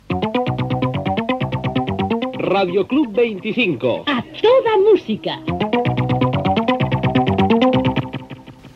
Indicatiu del programa.